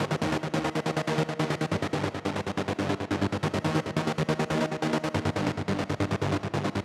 VTS1 Space Of Time Kit Bassline